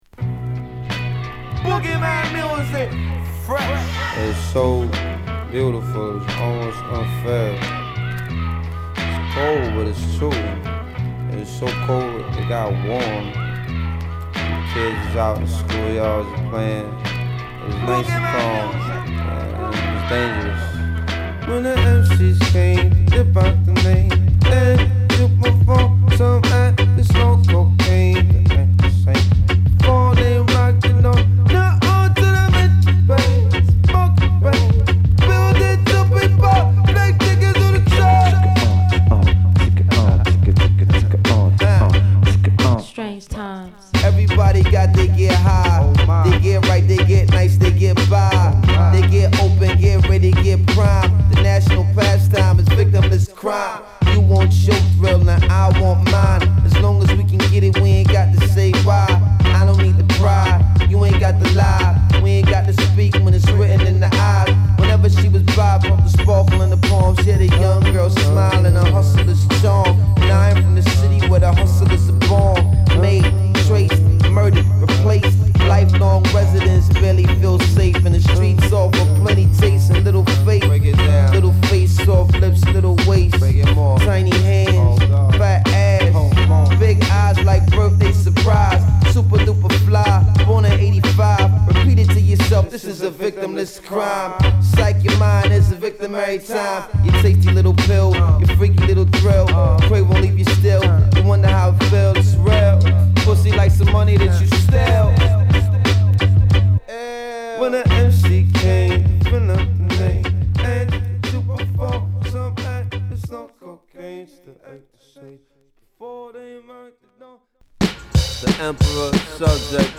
特にノイズは気になりません。